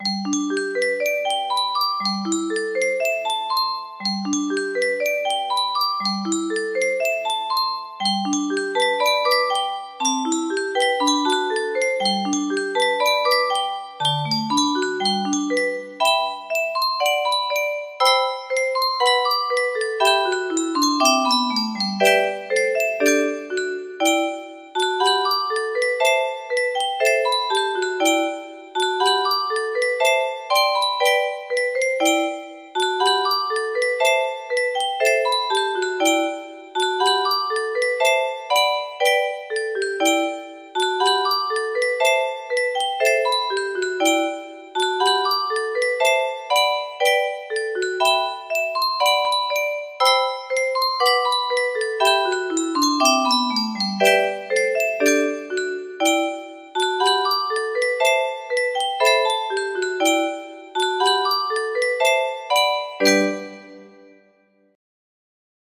Suo Gan-Empire of the sun music box melody